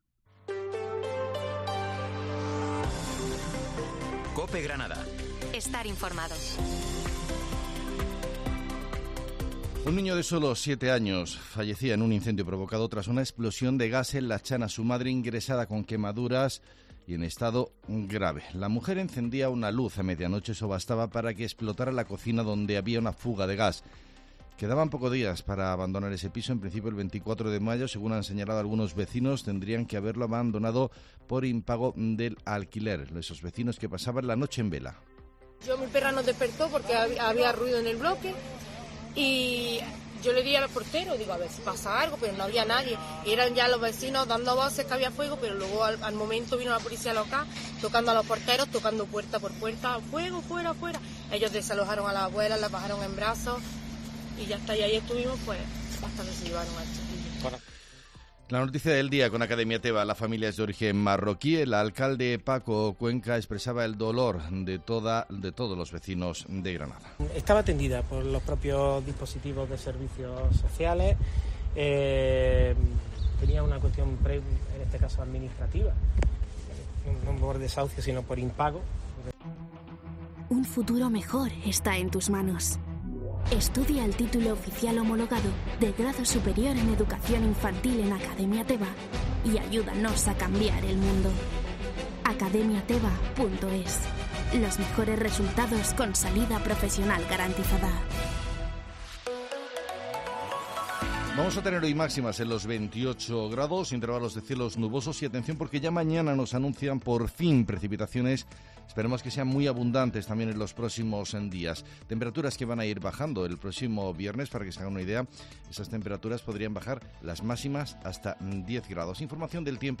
Herrera en COPE Granada. Informativo 16 de mayo de 2023